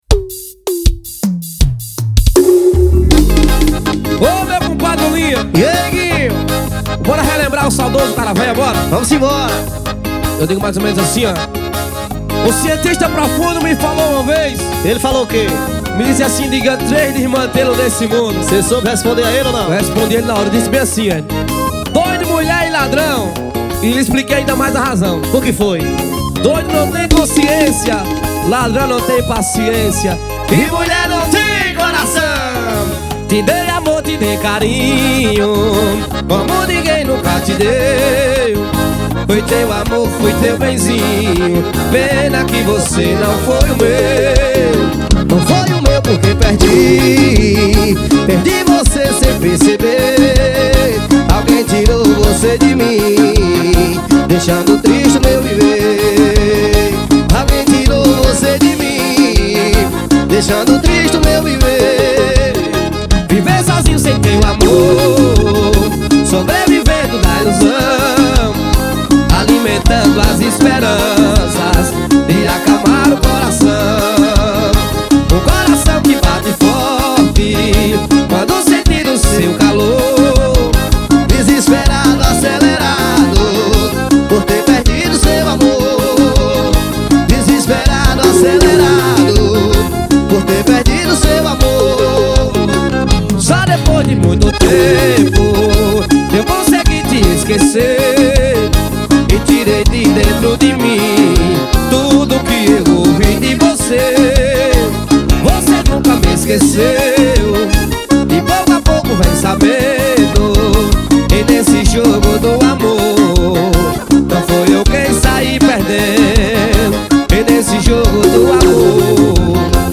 2024-02-14 18:15:46 Gênero: Forró Views